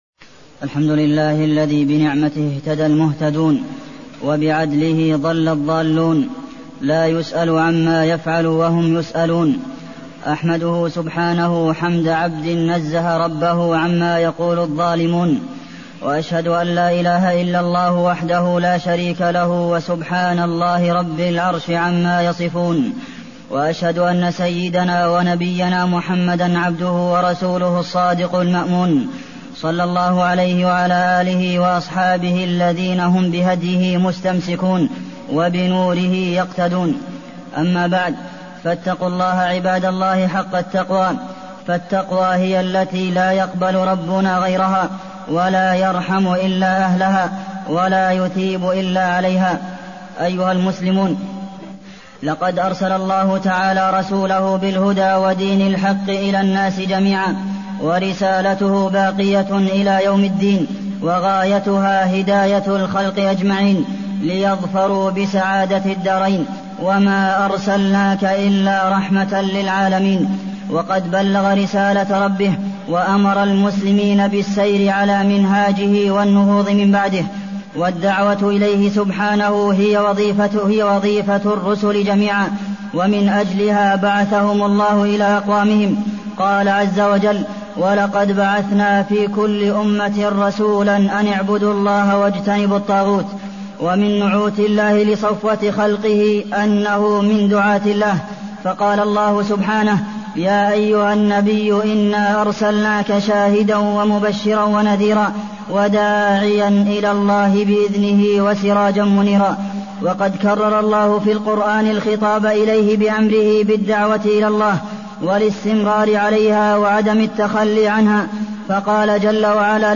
تاريخ النشر ١٤ ربيع الأول ١٤٢١ هـ المكان: المسجد النبوي الشيخ: فضيلة الشيخ د. عبدالمحسن بن محمد القاسم فضيلة الشيخ د. عبدالمحسن بن محمد القاسم صفات الداعية